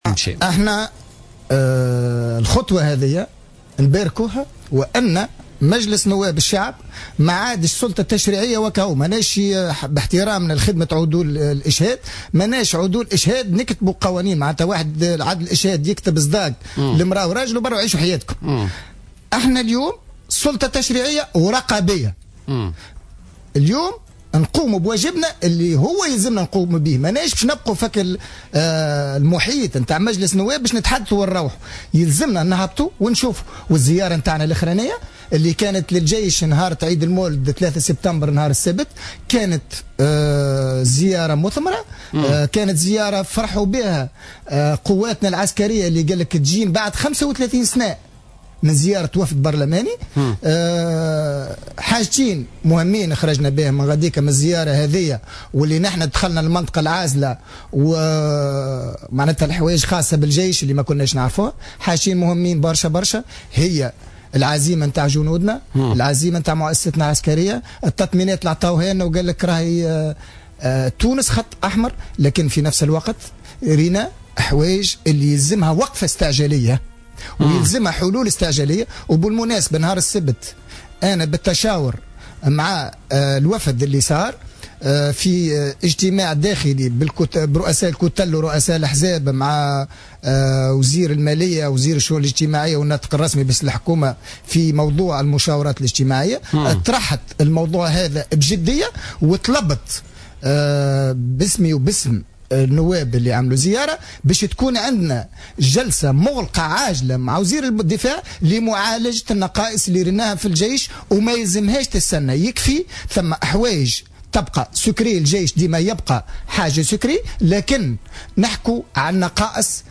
تحدّث نائب مجلس نواب الشعب عن حزب صوت الفلاحين،فيصل التبيني ضيف برنامج "بوليتيكا" اليوم عن الزيارة التي أداها الوفد البرلماني مؤخرا إلى المعبر الحدودي راس الجدير والمركز العسكري المتقدم سيدي التوي.